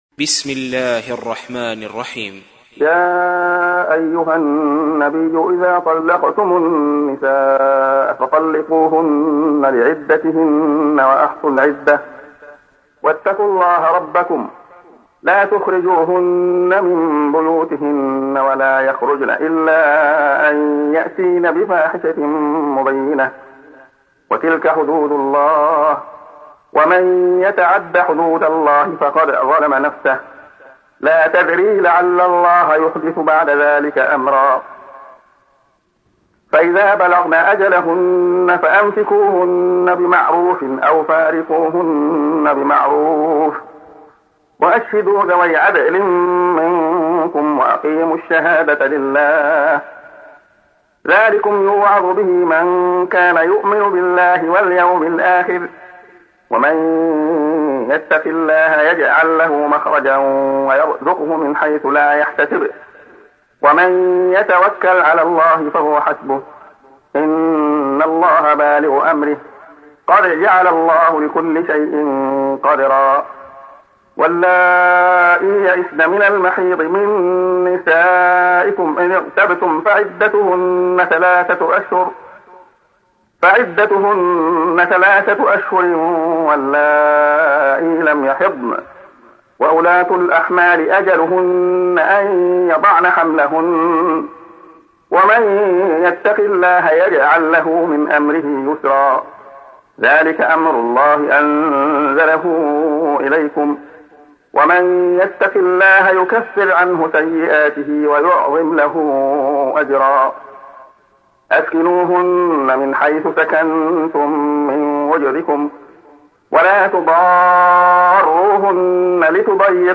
تحميل : 65. سورة الطلاق / القارئ عبد الله خياط / القرآن الكريم / موقع يا حسين